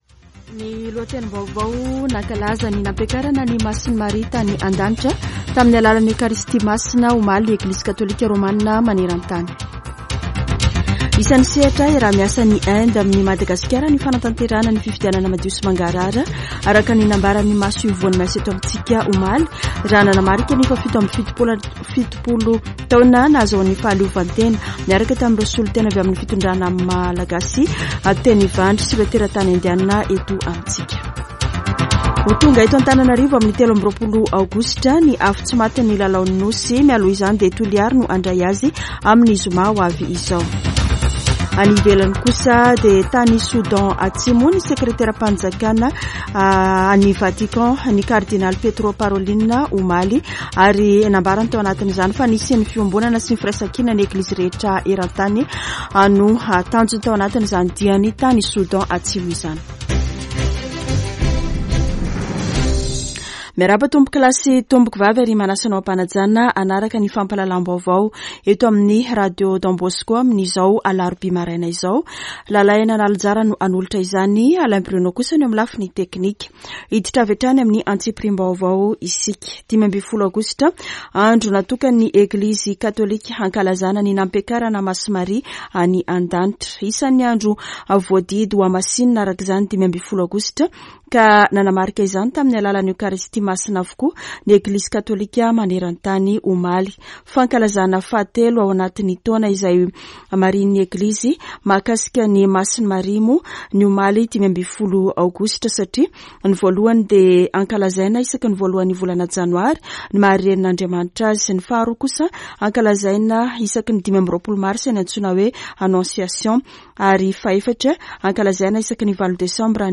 [Vaovao maraina] Alarobia 16 aogositra 2023